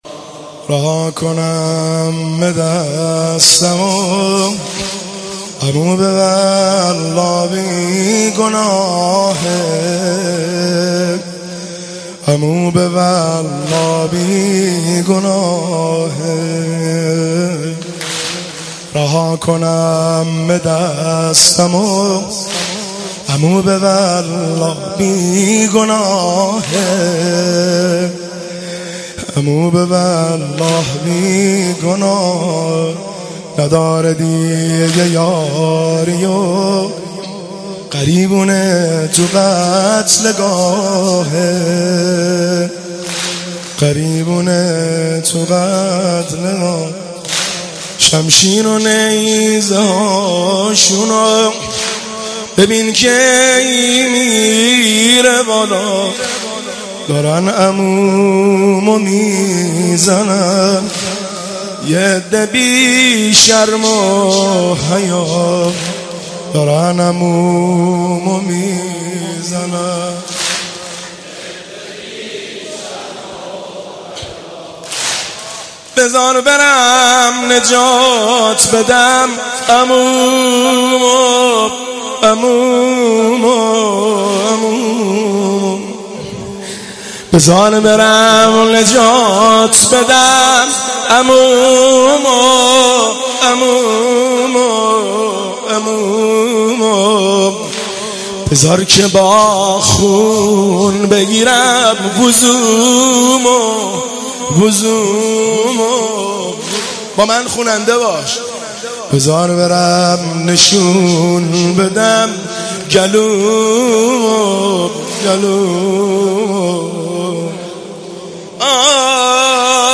هیئت بین الحرمین طهران شب پنجم محرم97